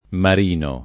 mar&no] pers. m. — tronc. di solito in Marin Faliero e in Marin Sanudo — più diffuso il femm. Marina — sim. i cogn. Marin [mar&n], Marina, -ni, ‑no, De Marini, ‑no, Di Marino e i top. Marini (Ven.), Marino (Lazio), San Marino — cfr. Gian Marino; Marin; Marina